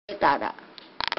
山口方言ライブラリ